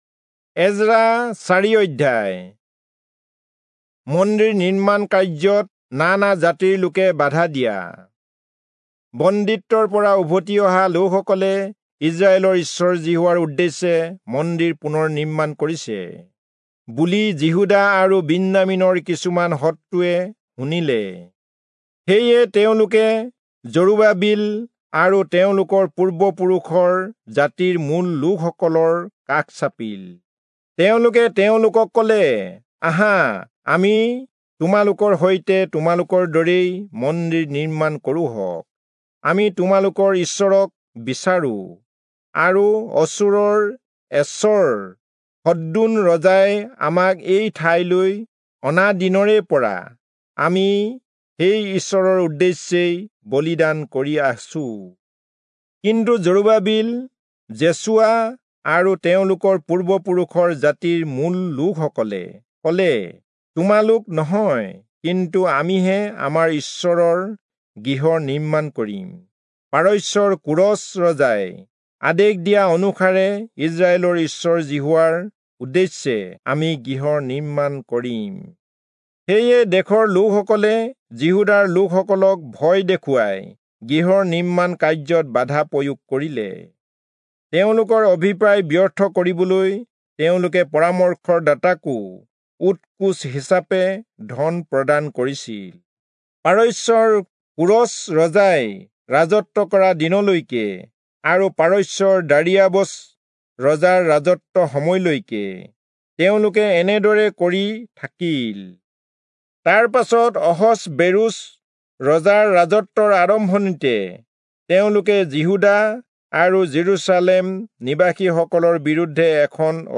Assamese Audio Bible - Ezra 10 in Wlc bible version